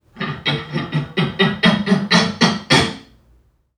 NPC_Creatures_Vocalisations_Robothead [86].wav